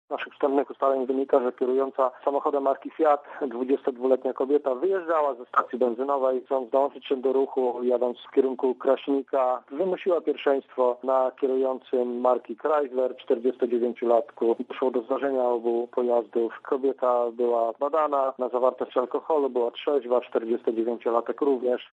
O szczegółach sprawy mówi aspirant